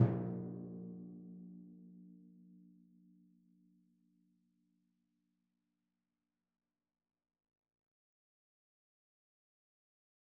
Timpani2_Hit_v3_rr2_Sum.wav